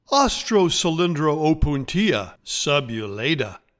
Pronounciation:
Aus-tro-cy-lin-dro-pun-TEE-a sub-u-LAY-ta